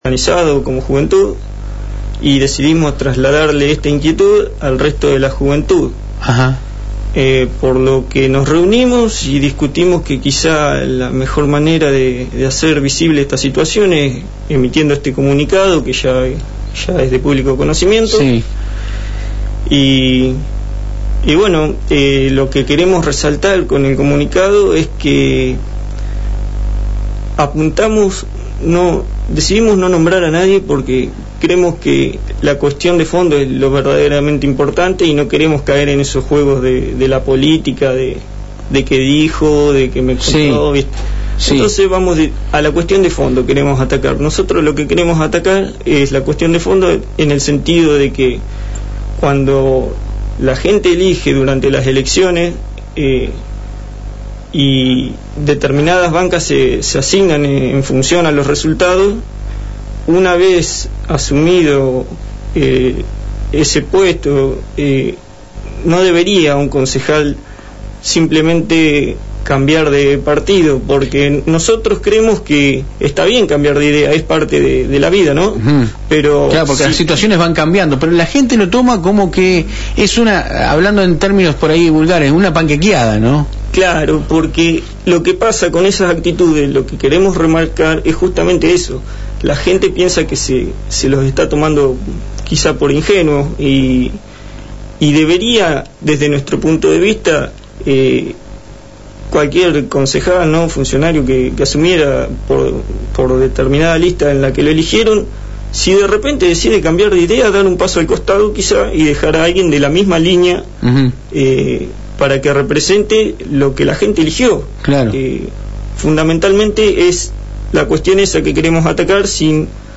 A continuacion podes descargar la nota de radio que se le hicieron a los integrantes de la juventud